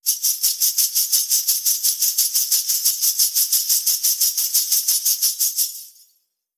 Danza árabe, bailarina haciendo el movimiento twist 02
continuo
moneda
Sonidos: Acciones humanas